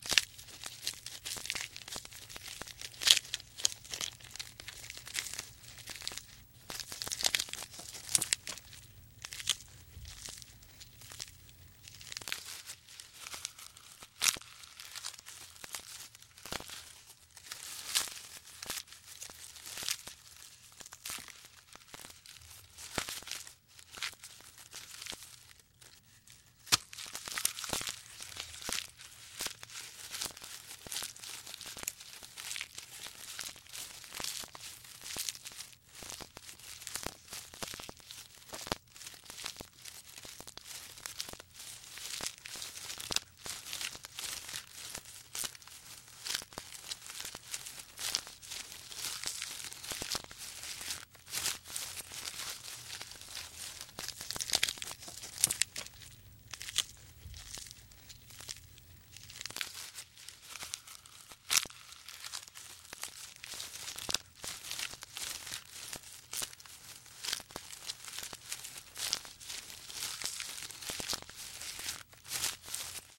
Звук поедания листьев жирафом с дерева